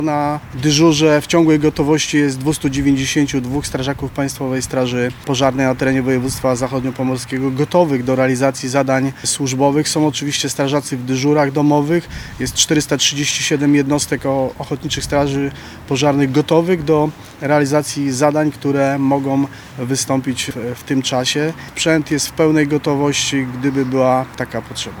Służby są w pełnej gotowości do działań podczas dzisiejszej nocy. Mówi st. bryg. Mirosław Pender – Komendant Wojewódzki Straży Pożarnej